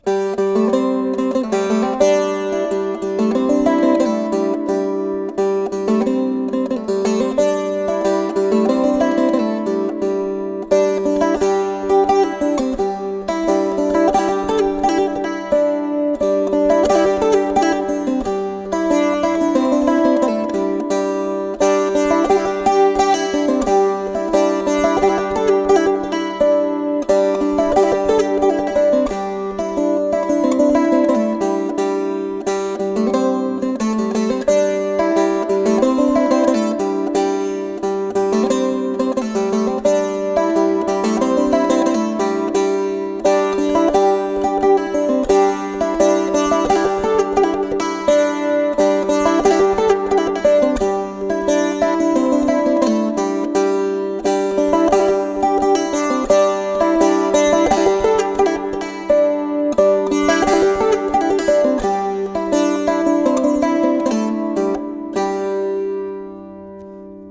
This piece for diatonic 17th c. cittern